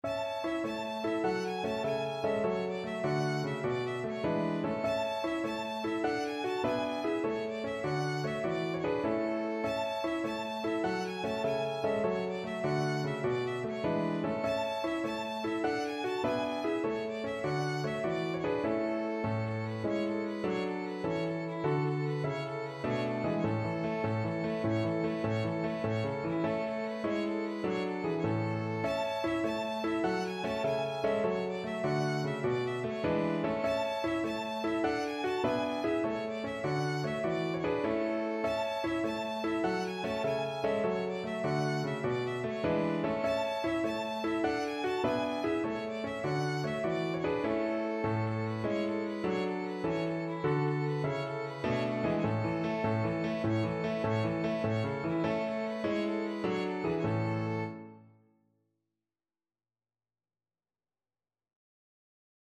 Free Sheet music for Violin
Violin
A major (Sounding Pitch) (View more A major Music for Violin )
6/8 (View more 6/8 Music)
~ = 150 A1
E5-A6
Traditional (View more Traditional Violin Music)